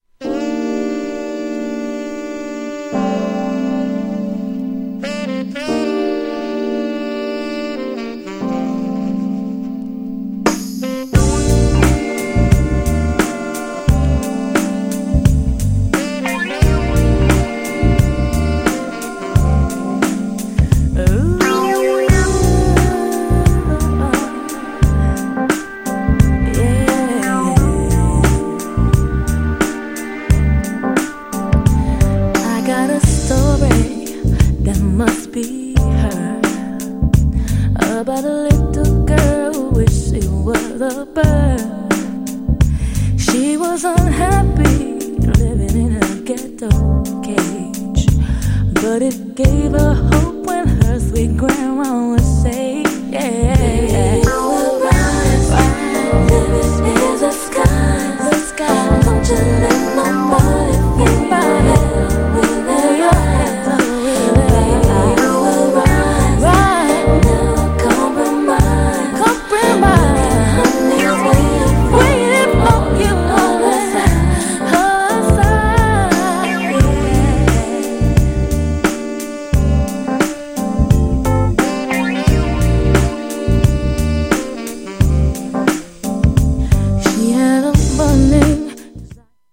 より力強くタフなビートに新たなキーが追加され、かなーり気持ち良い!!
GENRE R&B
BPM 91〜95BPM